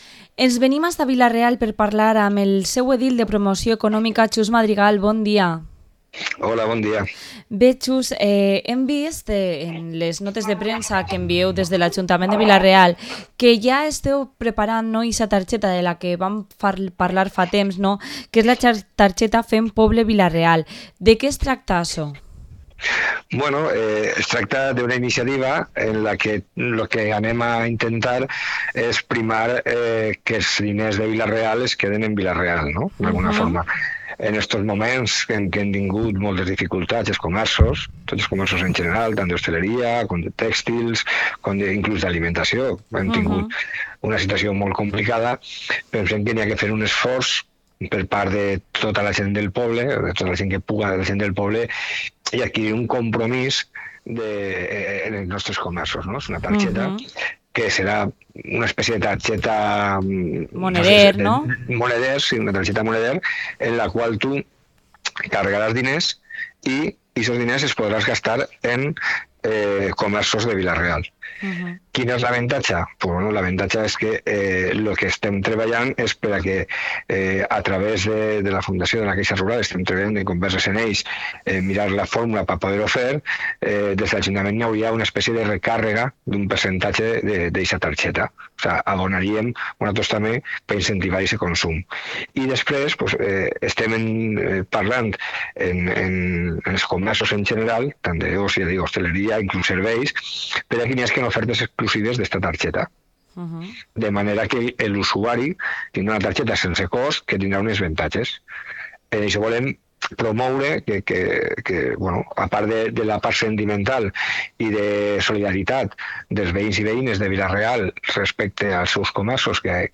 Entrevista al concejal de Economía en el Ayuntamiento de Vila-real, Xus Madrigal